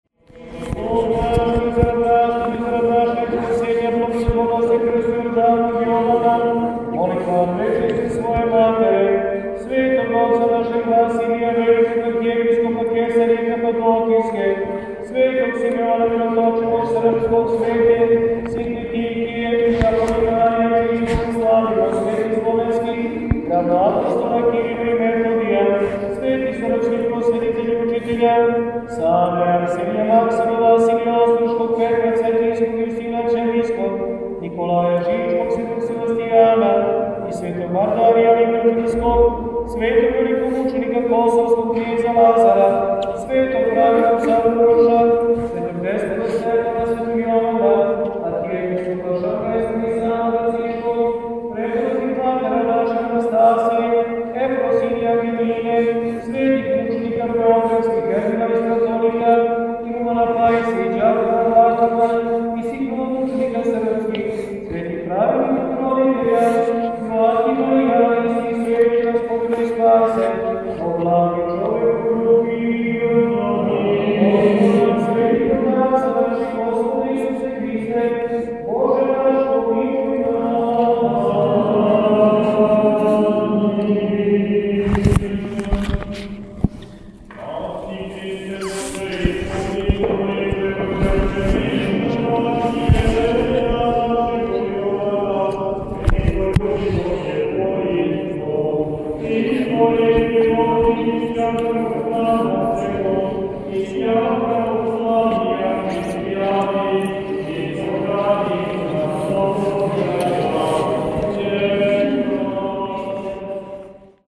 На празник Крстовдан, у понедељак 18. јануара 2021. године у цркви Светог Симеона Мироточивог на Новом Београду служени су Царски часови и Света Литургија.